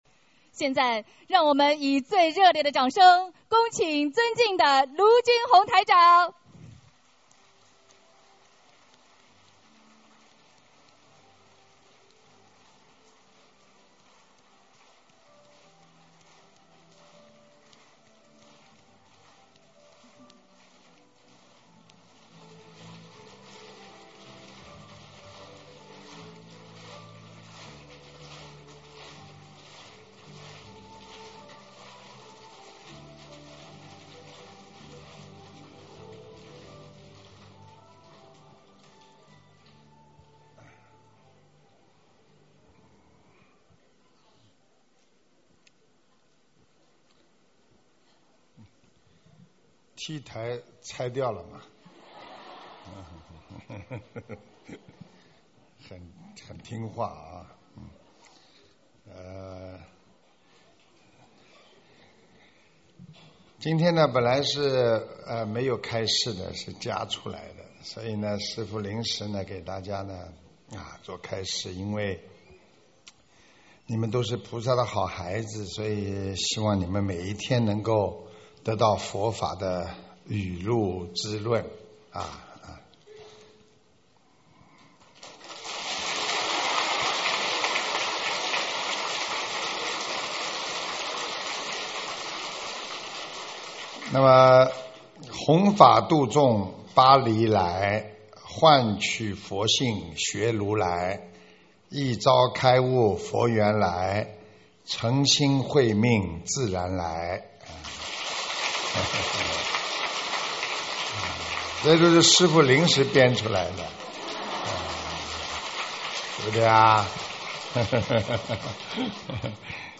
【2017法国·巴黎】9月28日 佛友见面会 文字+音频 - 2017法会合集 (全) 慈悲妙音